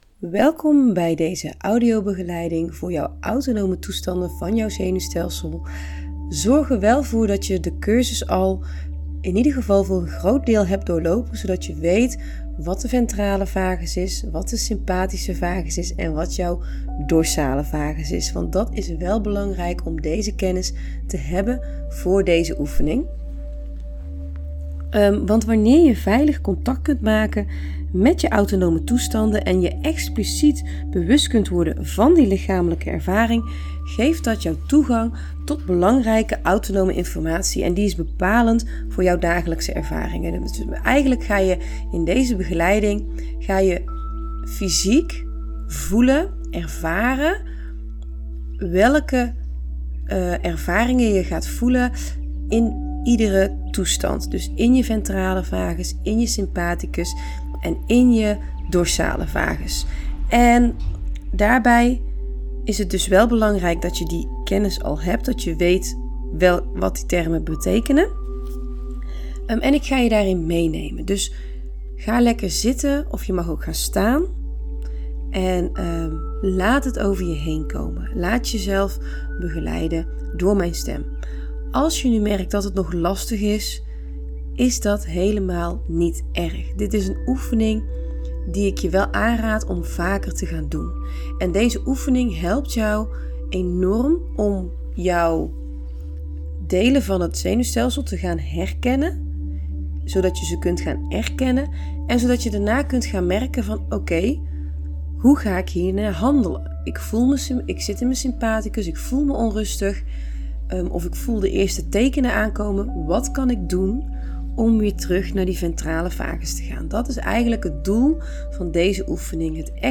Deze begeleide audio oefening helpt je om door de autonome toestanden van jouw zenuwstelsel te reizen. Op een veilige, gecontroleerde manier.